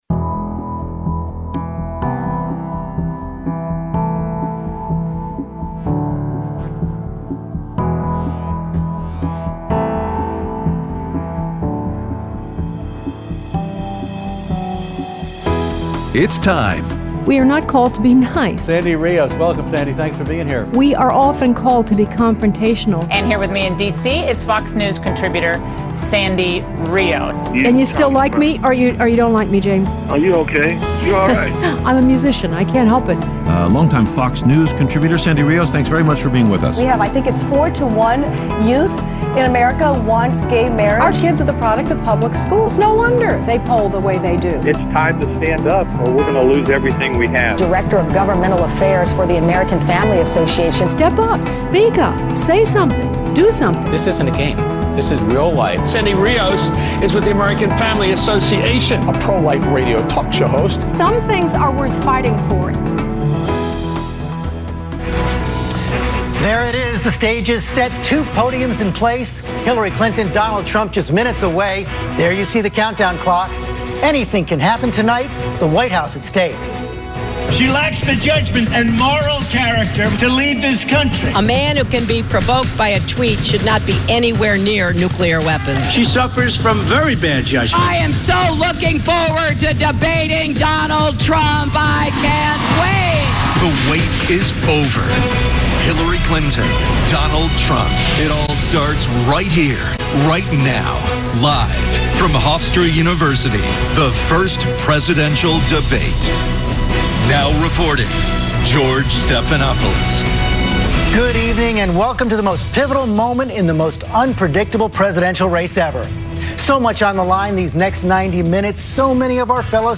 Aired Tuesday 9/27/16 on AFR Talk 7:05AM - 8:00AM CST